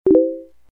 SFX – FACEBOOK 1
SFX-FACEBOOK-1.mp3